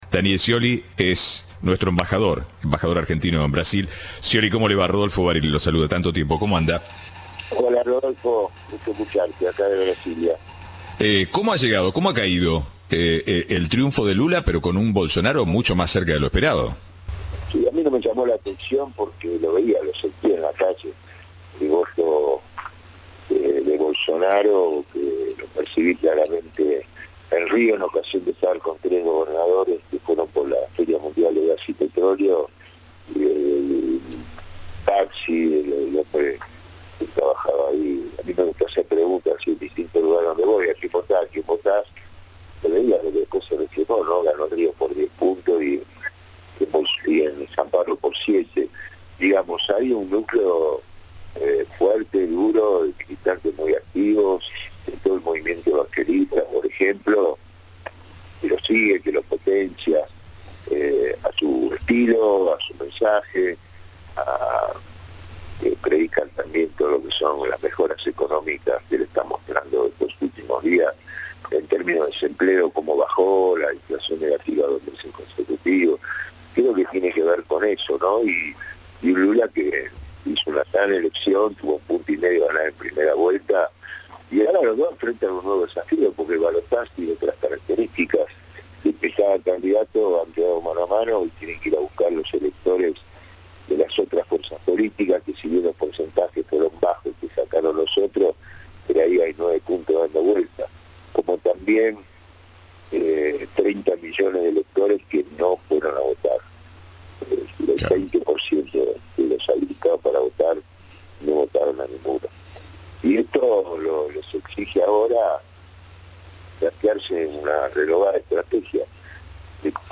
Cadena 3 habló con el embajador argentino en el país vecino tras conocerse el resultado de las urnas.
Entrevista de Rodolfo Barili.